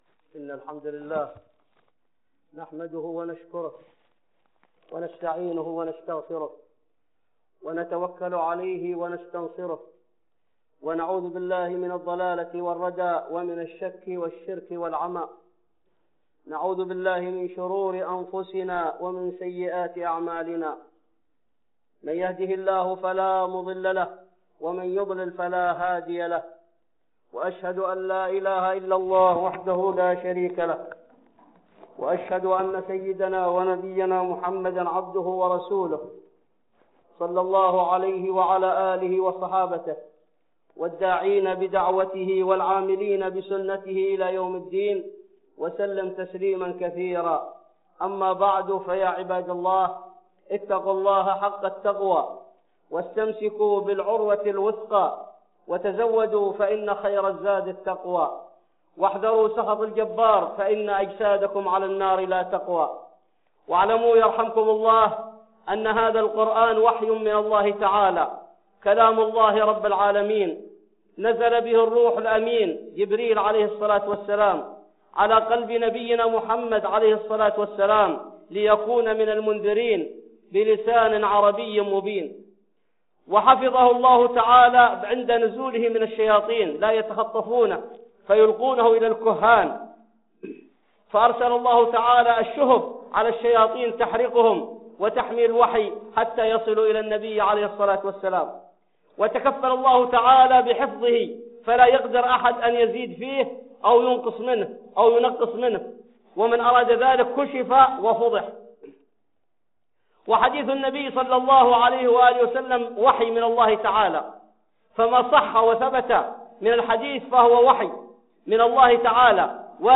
(خطبة جمعة) أحاديث وقصص ضعيفة وموضوعة